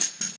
TAMB_HATS.wav